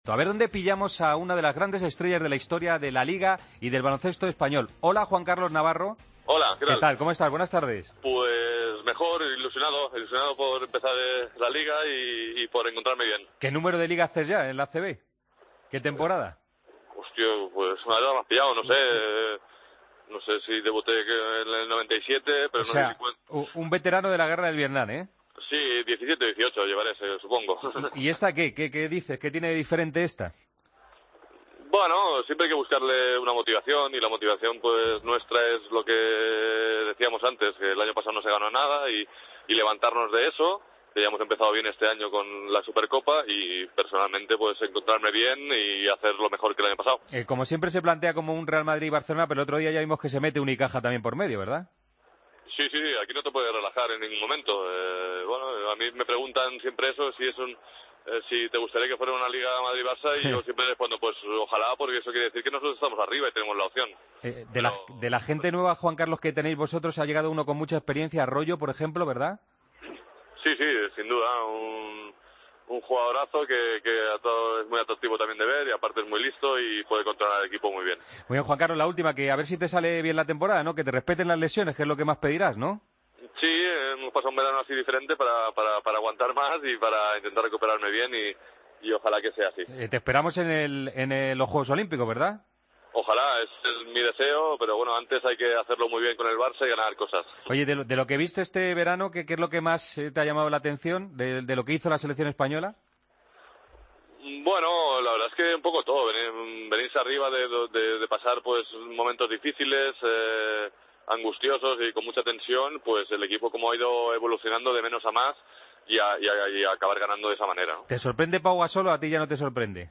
El jugador del Barcelona habló en Deportes COPE sobre la nueva temporada de la Liga Endesa que comienza este sábado y que ha sido presentada hoy. Afirma sentirse ilusionado con encontrarse bien y jugar bien.